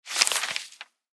crumble.wav.mp3